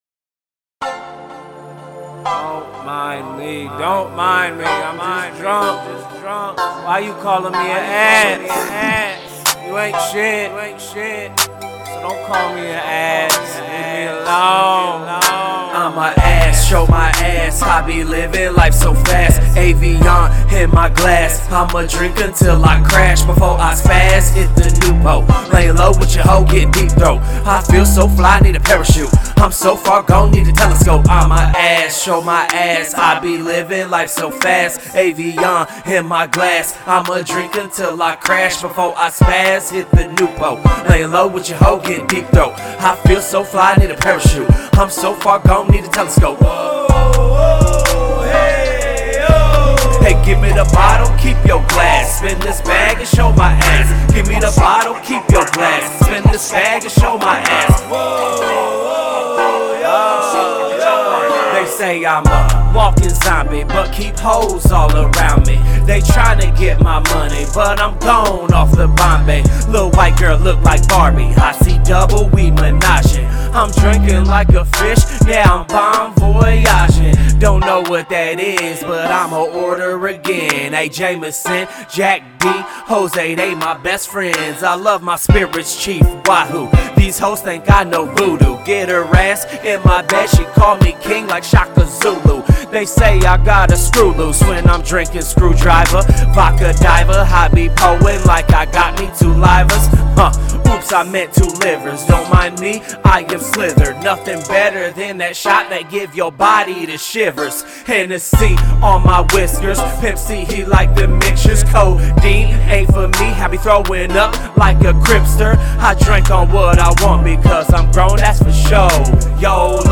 Hiphop
Description : OHIO Hip-Hop Muzik!!!